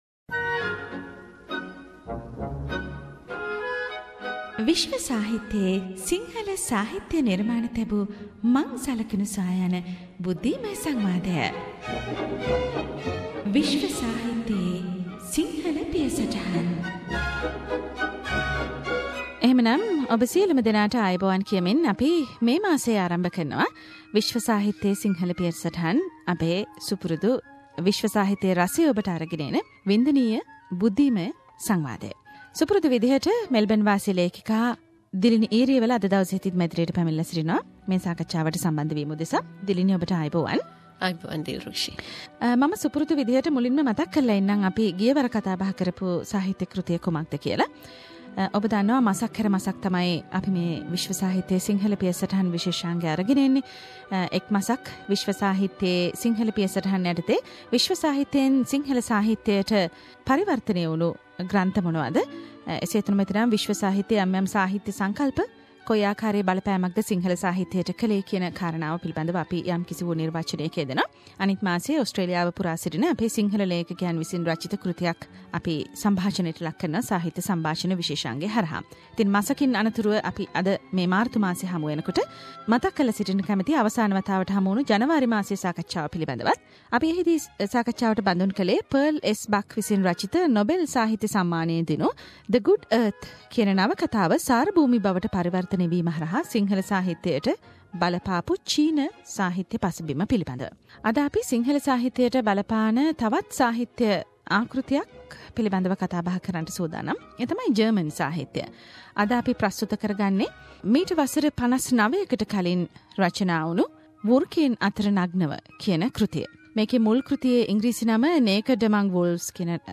SBS Sinhalese monthly world literary discussion: Naked among Wolves